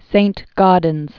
(sānt-gôdnz), Augustus 1848-1907.